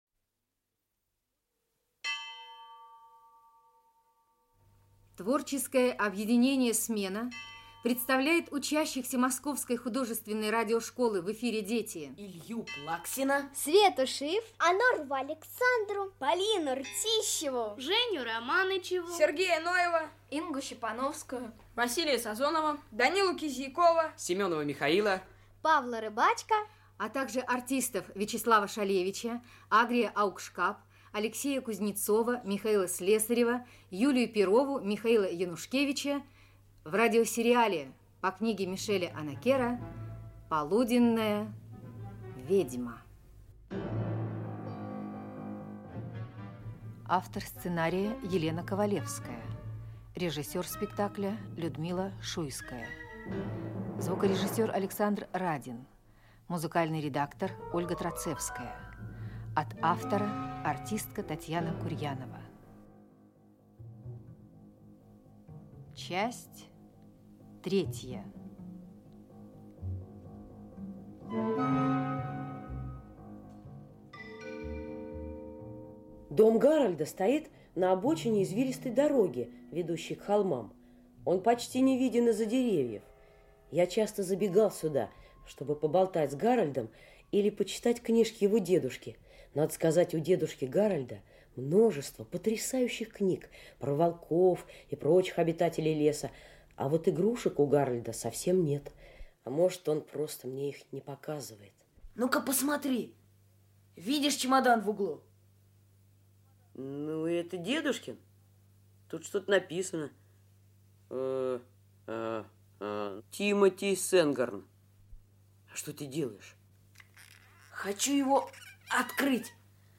Аудиокнига Полуденная ведьма. Часть 3 | Библиотека аудиокниг
Часть 3 Автор Мишель Онакер Читает аудиокнигу Вячеслав Шалевич.